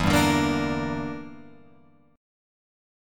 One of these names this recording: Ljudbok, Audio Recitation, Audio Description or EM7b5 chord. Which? EM7b5 chord